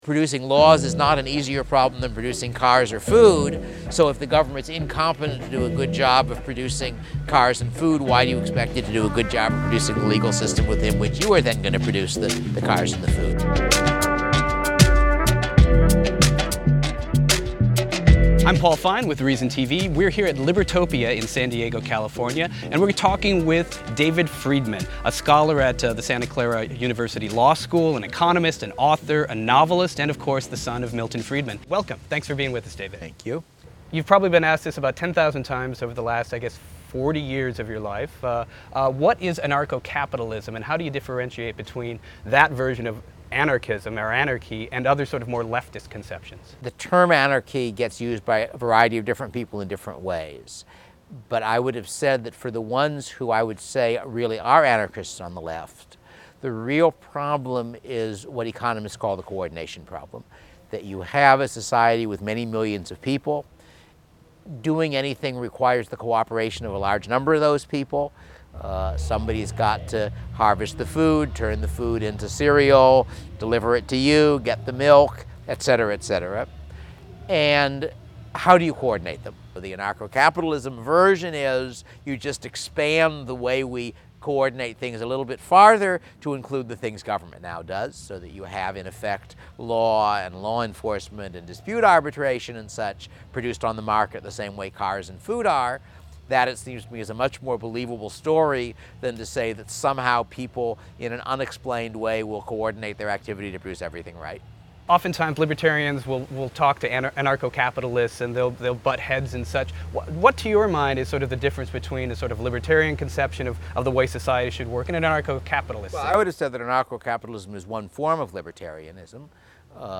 Friedman sat down to talk with Reason TV at Libertopia 2012 in San Diego. Friedman reflected on the impact of his landmark book, The Machinery of Freedom, discussed the differences between libertarianism and anarcho-capitalism and revealed what his father, economist Milton Friedman, thought of his anarchist leanings.
Interview